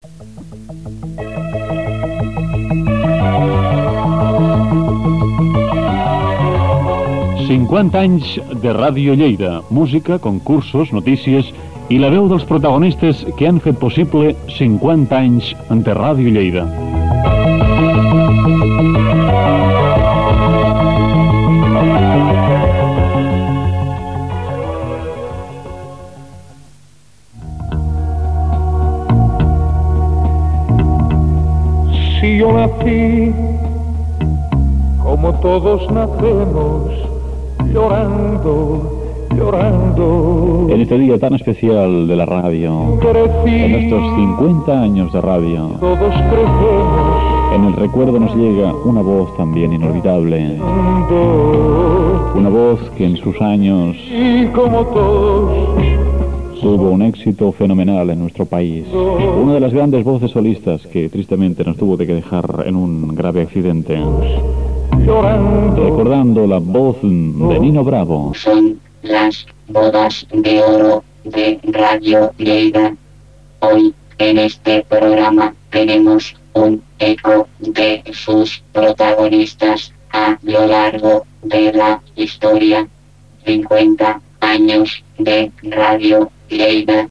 Identificació del programa, cançó, veu sintètica